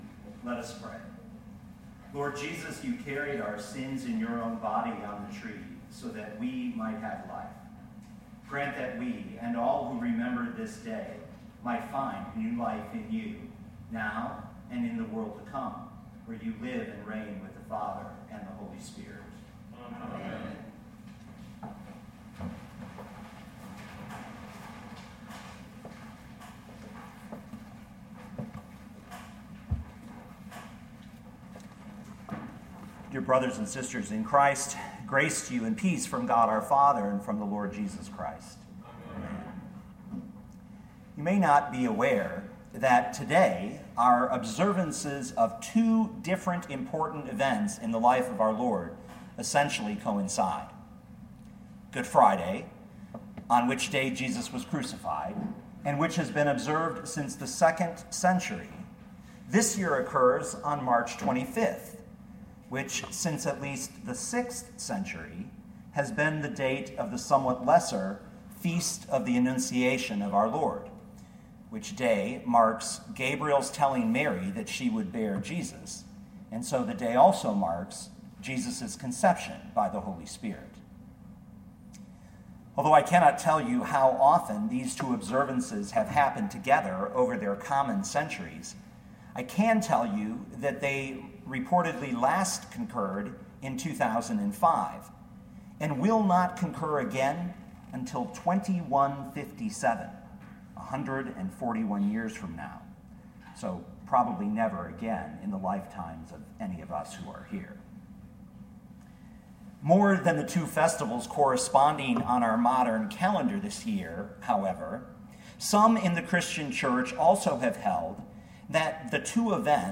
2016 Luke 1:26-38 Listen to the sermon with the player below, or, download the audio.